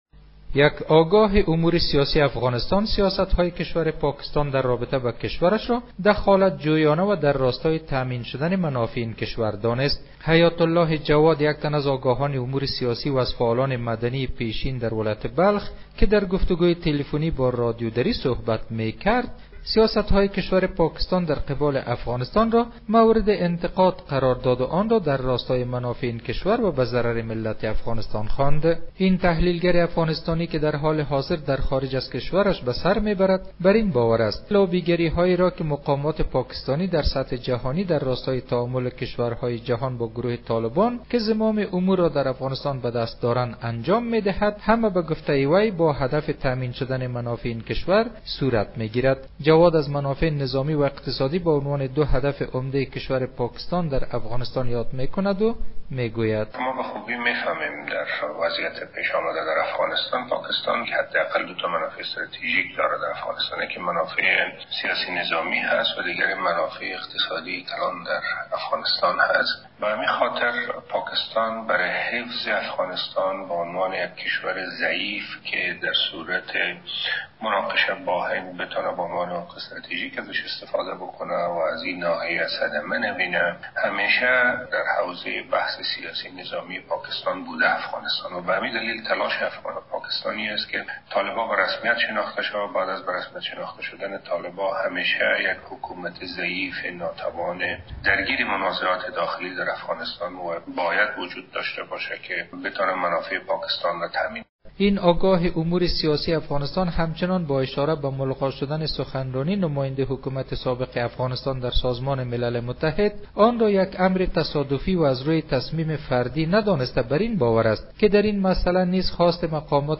درگفتگوی تلفنی با رادیو دری